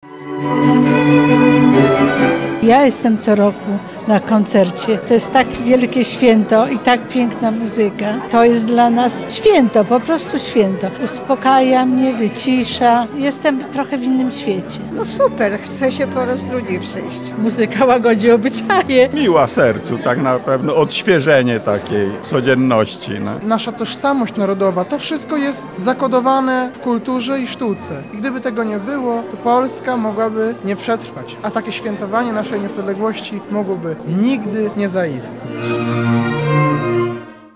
Lublinianie licznie przybyli na koncert, aby świętować przy dźwiękach muzyki.